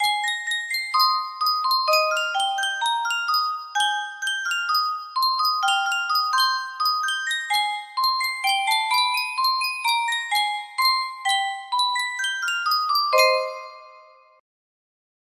BPM 64